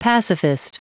Prononciation du mot pacifist en anglais (fichier audio)
Prononciation du mot : pacifist